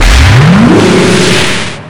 BombMerged.wav